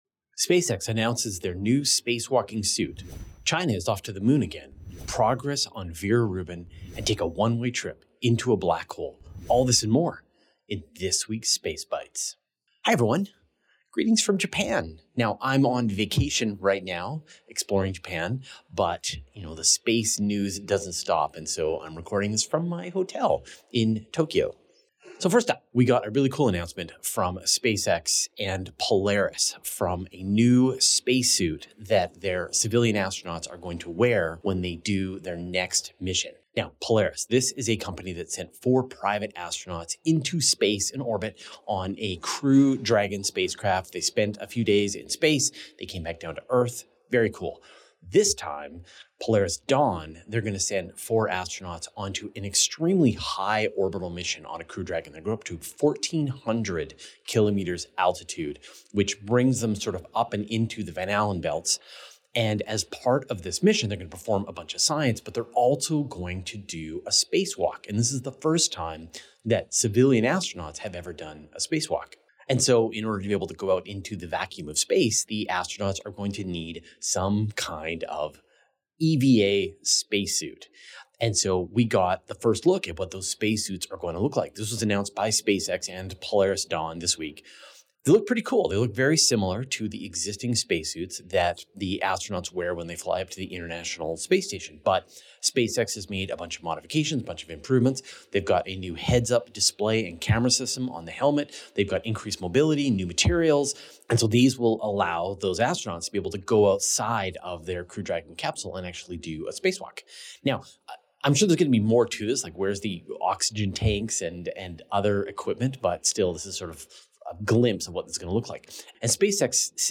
[Q&A] Rogue Planets with Liquid Oceans, Post JWST Telescope Size, Non-EM Space Communication